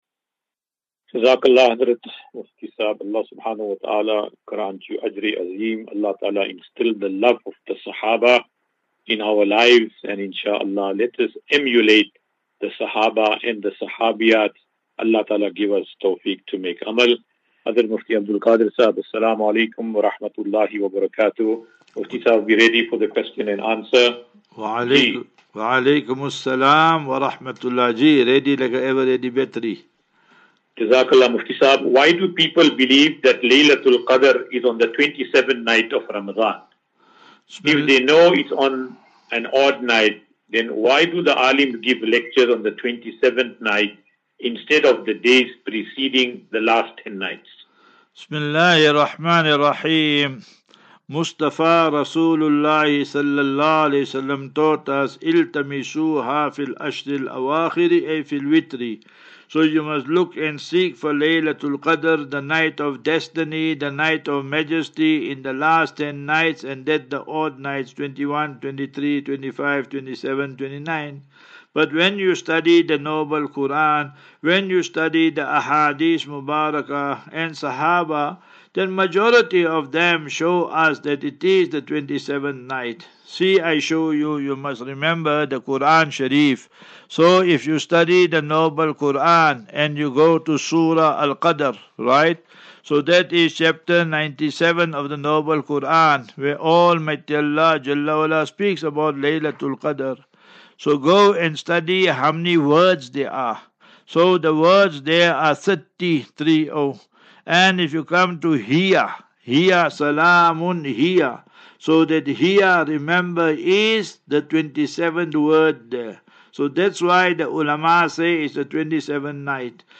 As Safinatu Ilal Jannah Naseeha and Q and A 28 Mar 28 March 2024.